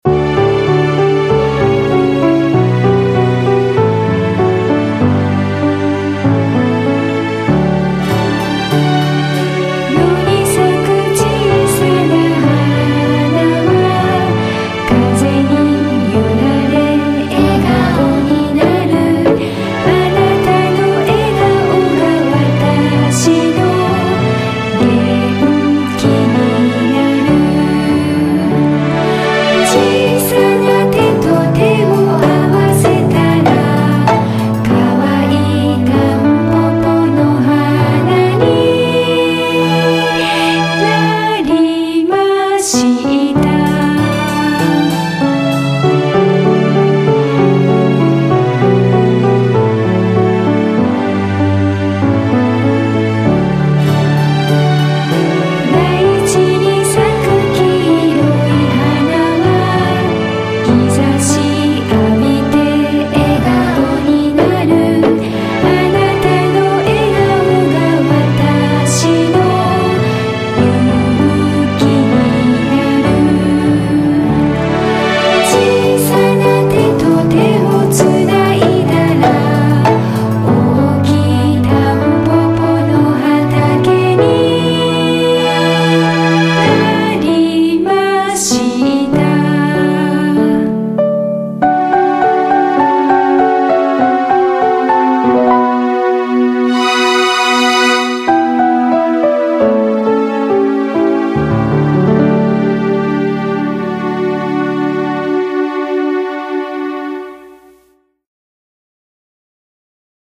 このページで流れているＢＧＭは、たんぽぽの「園歌」です。
この曲を聴いていると「まあるい」笑顔で「ほわほわ」した気持ちになりませんか？